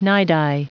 Prononciation du mot nidi en anglais (fichier audio)
Prononciation du mot : nidi